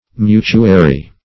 Mutuary \Mu"tu*a*ry\, n. [L. mutuarius mutual.See Mutuation.]